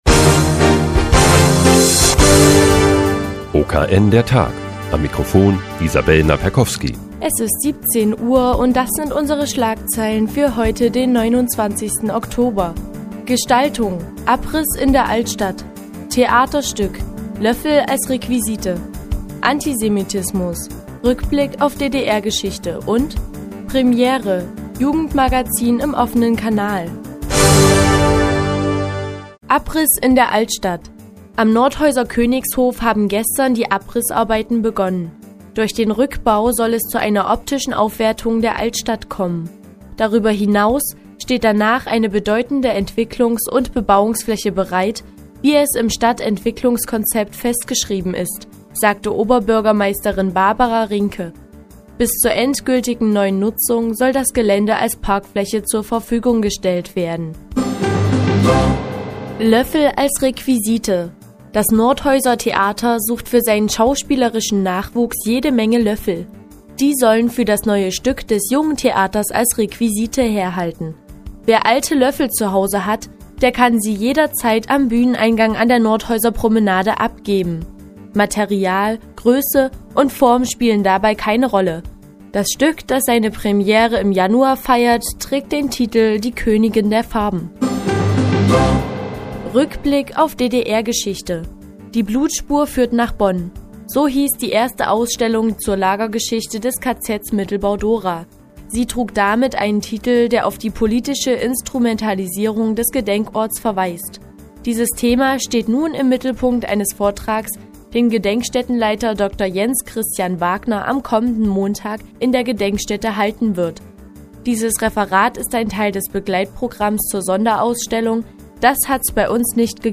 Die tägliche Nachrichtensendung des OKN ist nun auch in der nnz zu hören. Heute geht es um eine Requisitenbeschaffung der besonderen Art und ein neues Magazin im OKN.